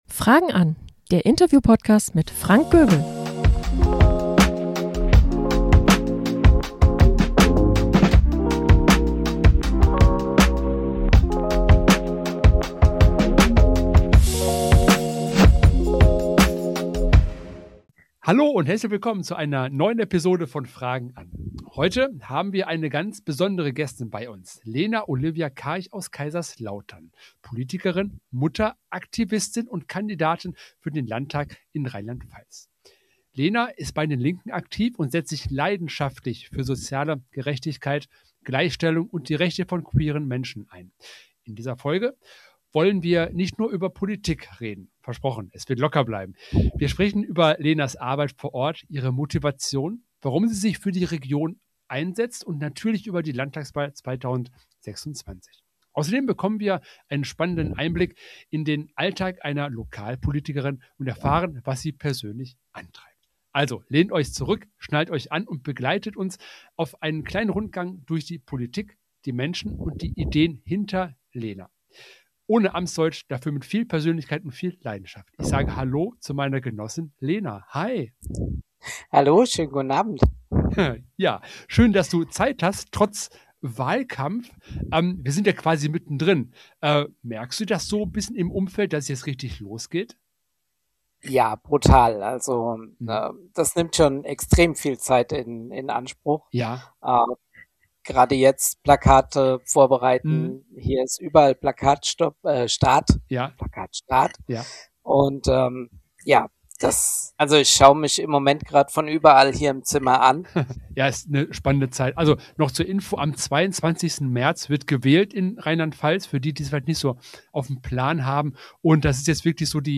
Freut euch auf ein offenes Gespräch über Politik zum Anfassen, Motivation, regionale Perspektiven – und die Frage, wie man Veränderung ganz praktisch angeht. Themen dieser Folge: – Einstieg in die Lokalpolitik und persönliche Motivation – Politische Arbeit zwischen Alltag und Engagement – Einblicke rund um die Landtagswahl in Rheinland-Pfalz – Herausforderungen und Chancen in der Region Viel Spaß beim Zuhören!